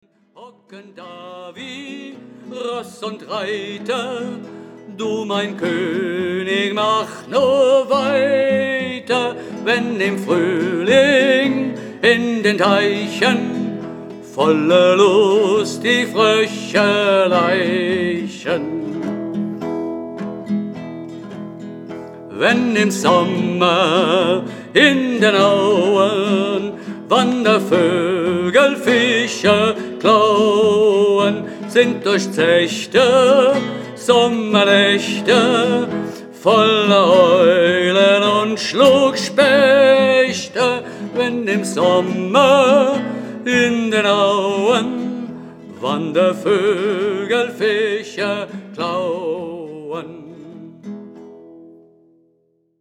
Instrumentalsätze